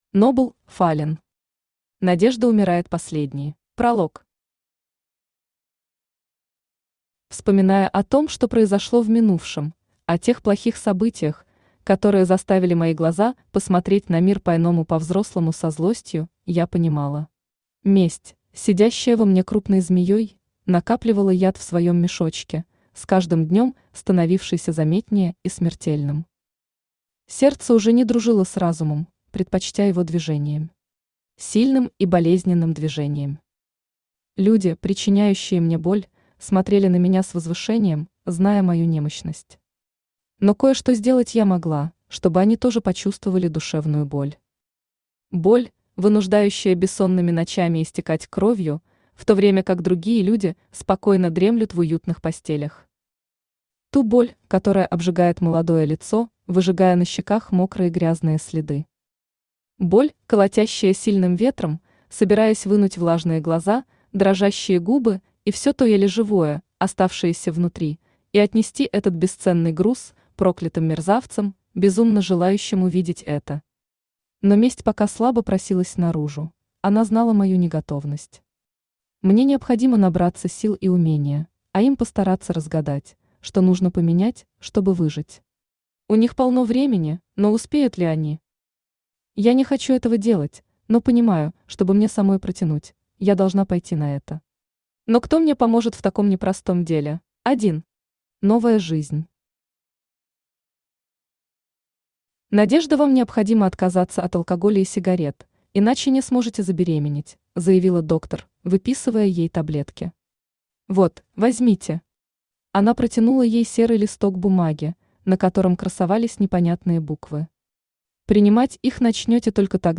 Аудиокнига Надежда умирает последней | Библиотека аудиокниг
Aудиокнига Надежда умирает последней Автор Нобл Фаллен Читает аудиокнигу Авточтец ЛитРес.